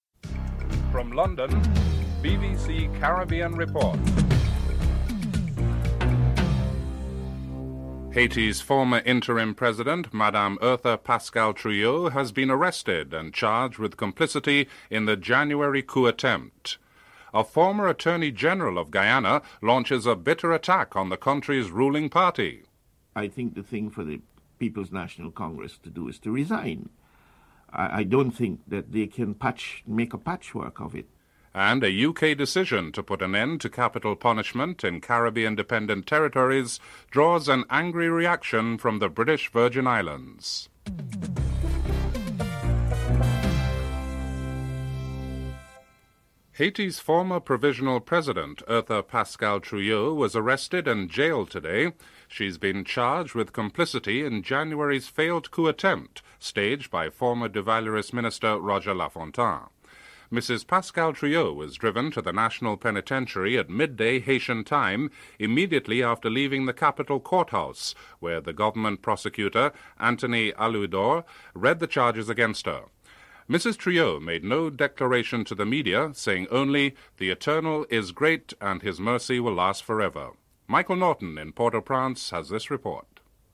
3. Suriname’s interim government led under President Kragg has been accused of emptying the treasury with unnecessary expenditures to give its party an edge over the others at the May 25th elections.